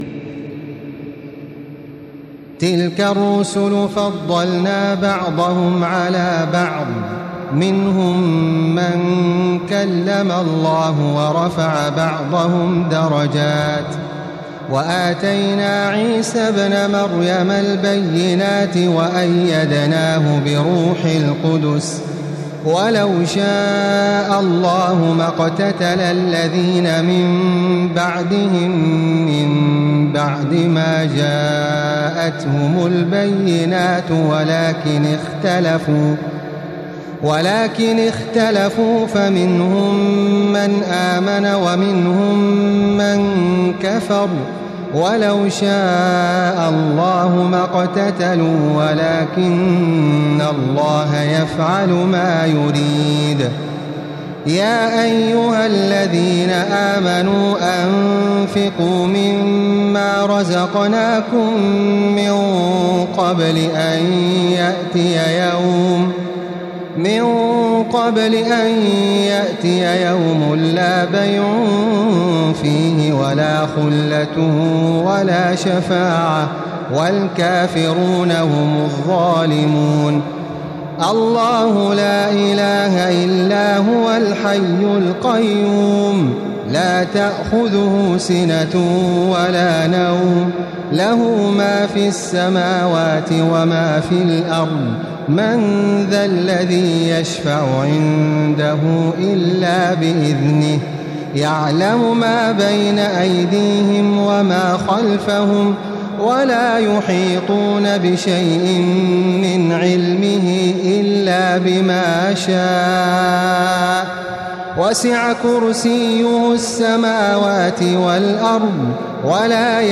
تراويح الليلة الثالثة رمضان 1435هـ من سورتي البقرة (253-286) و آل عمران (1-18) Taraweeh 3st night Ramadan 1435H from Surah Al-Baqara and Surah Aal-i-Imraan > تراويح الحرم المكي عام 1435 🕋 > التراويح - تلاوات الحرمين